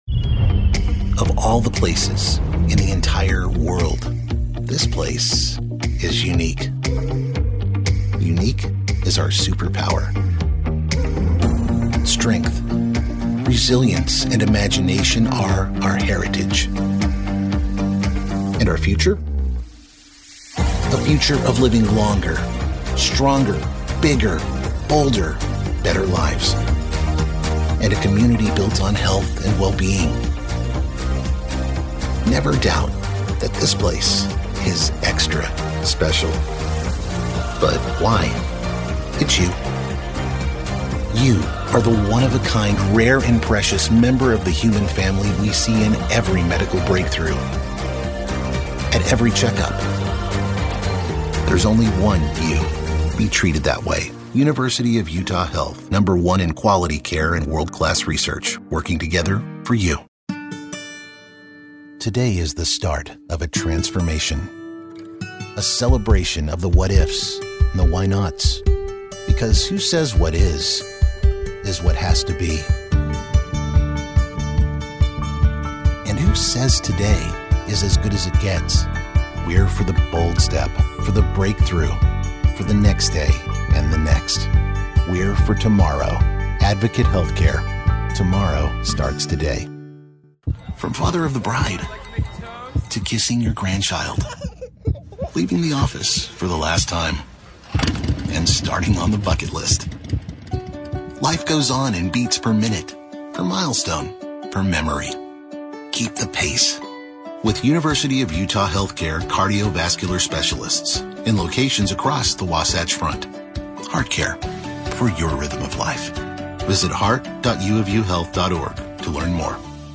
Commercial - Caring & Compassionate
All vocals are recorded in a professional studio with state of the art equipment including Sennheiser MKH-416, Avalon 737 preamp, and Adobe Audition DAW.